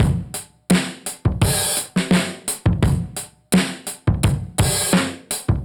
Index of /musicradar/dusty-funk-samples/Beats/85bpm/Alt Sound